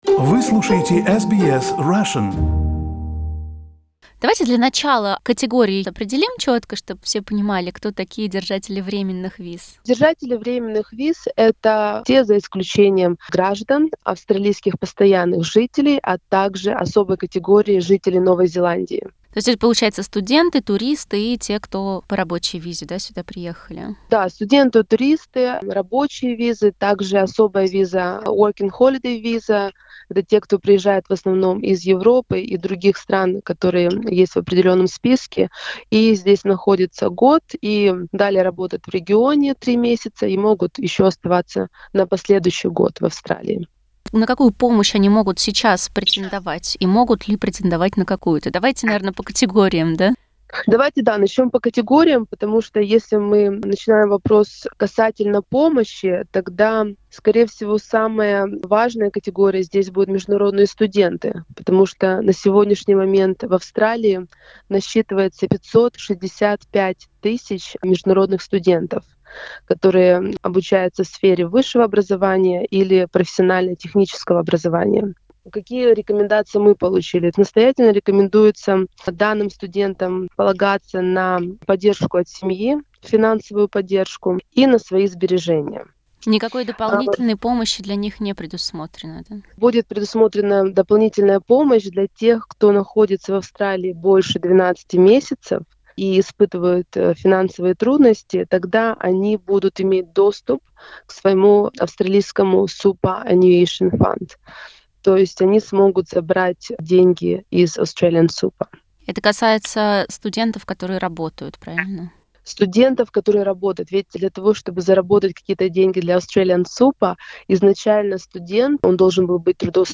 What help can people on temporary visas get? Interview with an expert